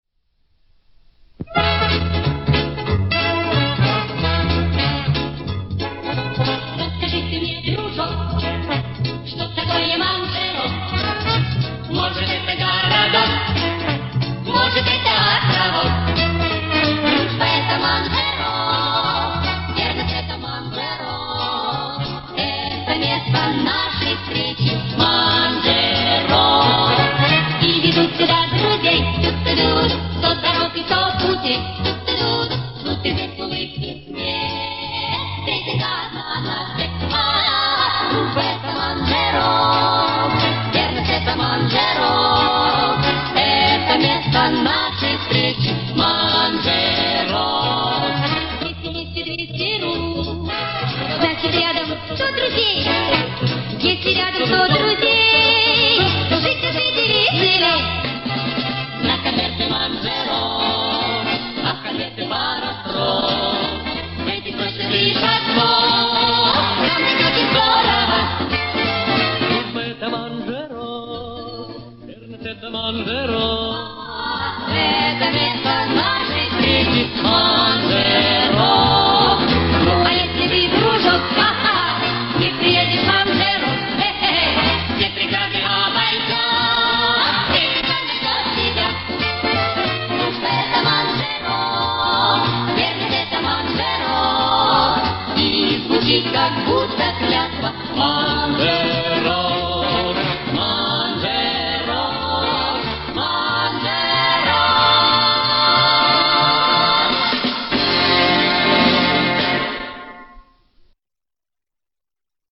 Это концертная запись